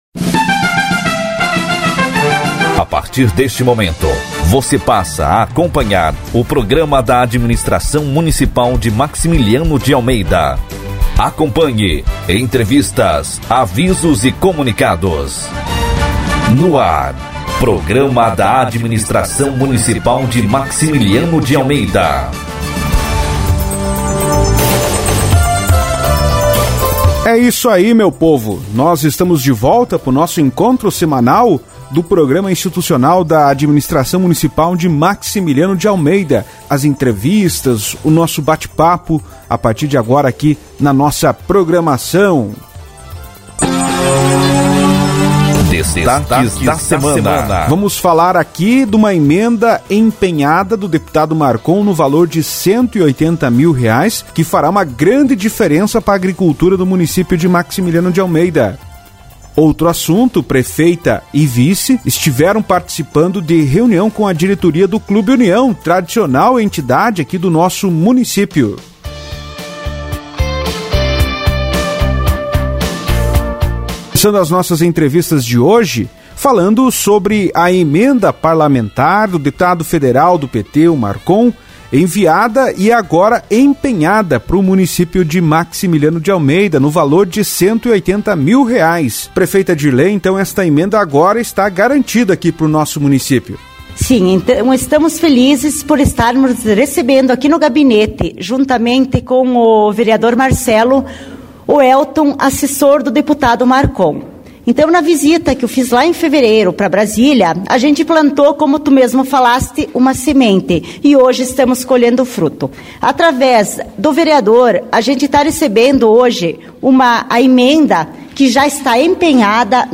Programa de Rádio Semanal – Município tem Emenda de R$ 180.000,00 do Deputado Marcon empenhada e Reunião com a nova diretoria do Clube União e Prefeita e Vice